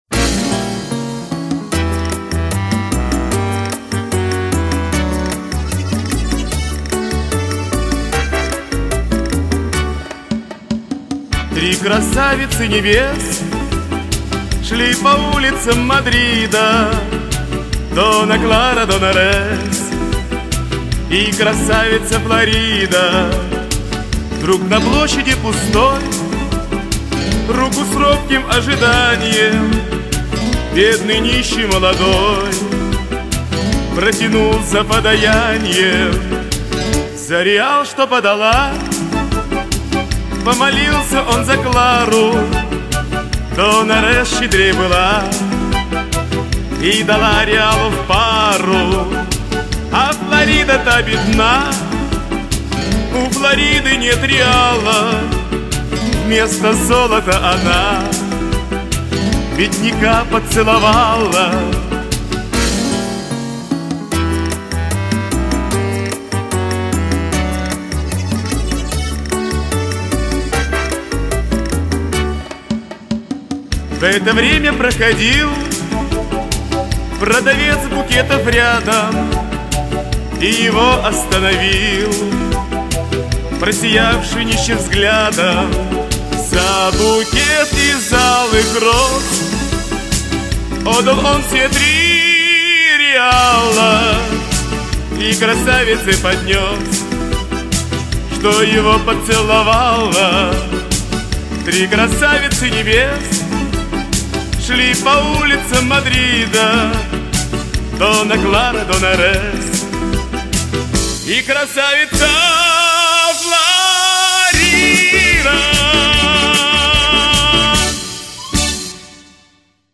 Народная испанская песня начала ХХ века о трех мадридских красавицах и молодом бедняке.